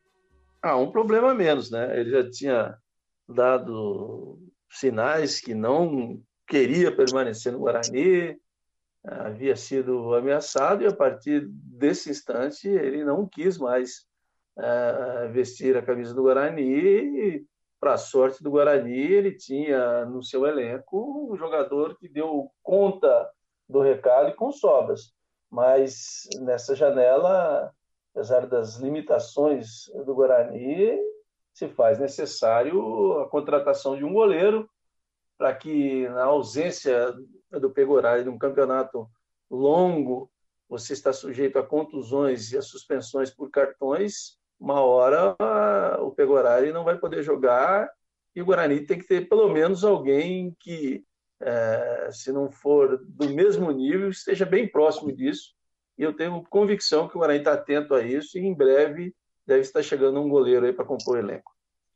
Comentaristas da Rádio Brasil analisam as movimentações da janela de transferências